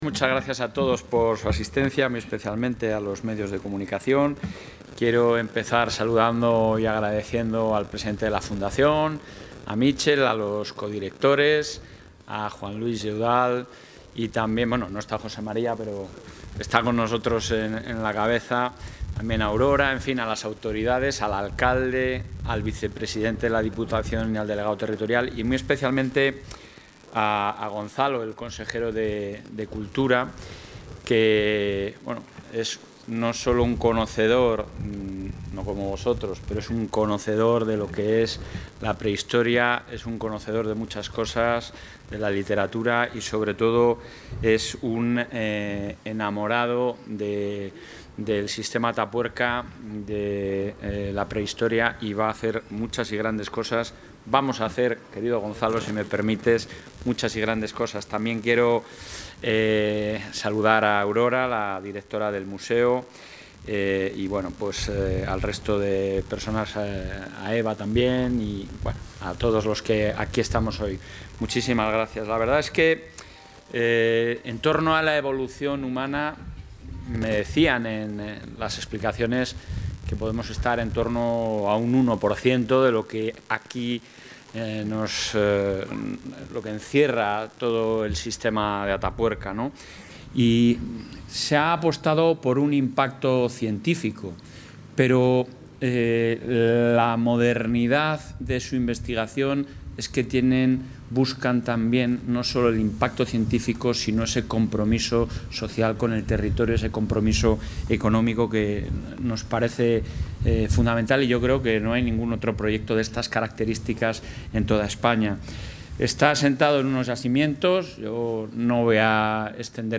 Intervención del presidente de la Junta.
El presidente de la Junta de Castilla y León, Alfonso Fernández Mañueco, ha visitado hoy, en Burgos, el Sitio Arqueológico de Atapuerca, enclave que se ha comprometido a seguir preservando e impulsando. Así pues, ha confirmado que la Junta cofinanciará la ampliación de la sede de la Fundación para albergar la memoria del Sistema Atapuerca y el fondo documental de Emiliano Aguirre.